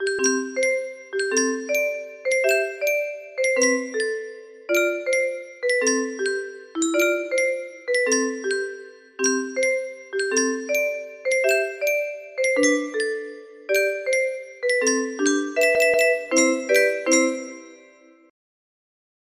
30603 music box melody